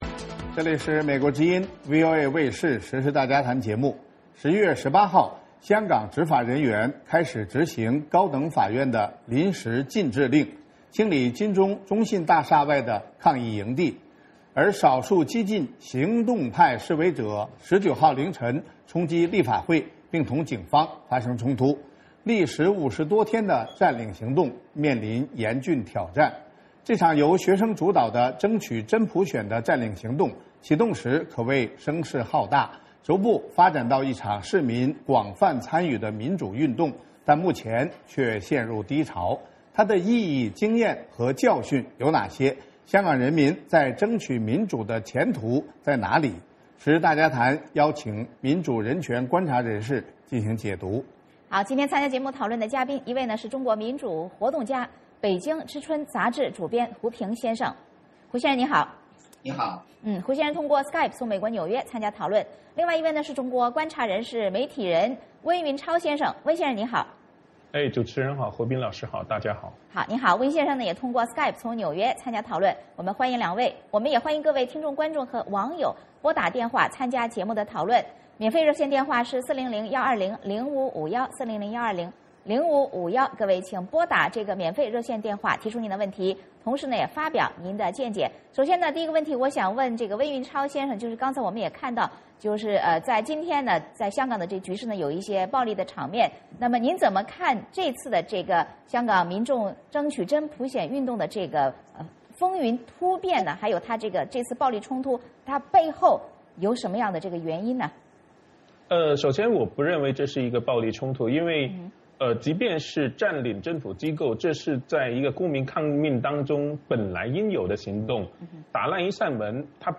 香港人民争取民主的前途在哪里？时事大家谈邀请民主人权观察人士进行解读。